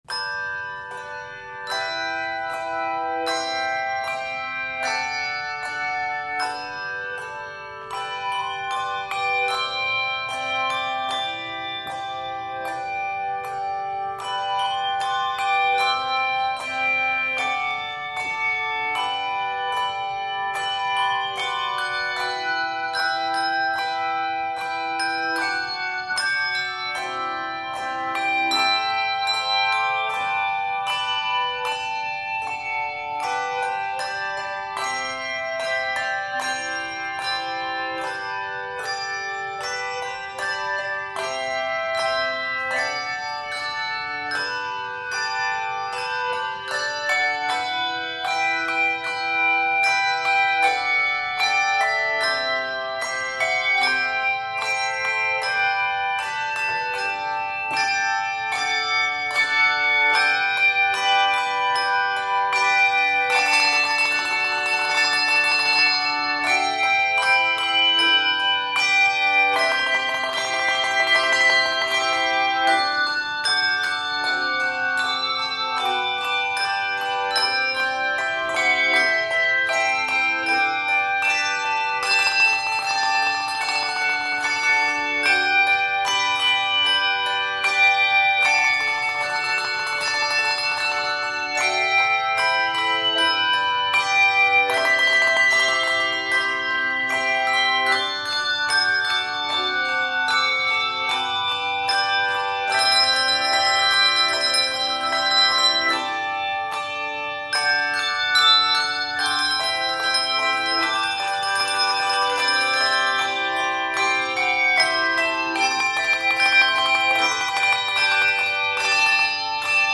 Octaves: 2-5